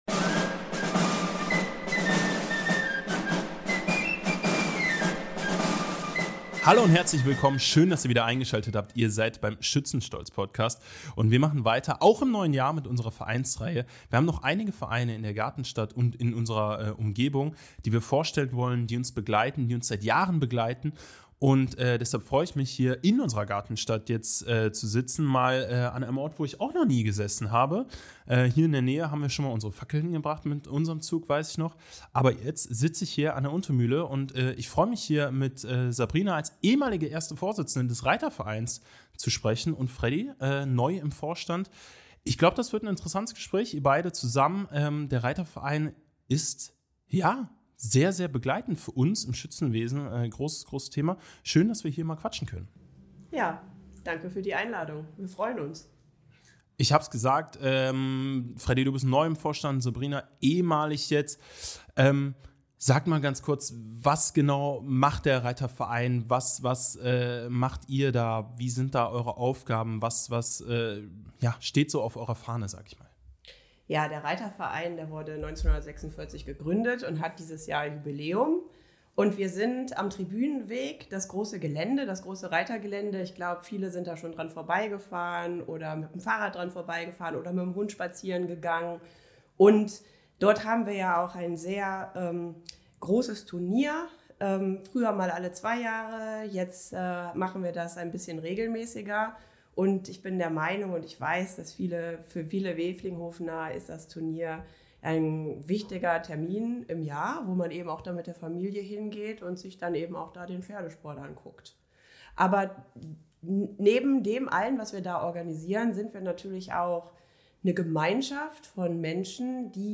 Pferde und Schützenfest – Tradition oder Risiko? Der Reitverein Wevelinghoven im Gespräch (#50) ~ Schützenstolz Podcast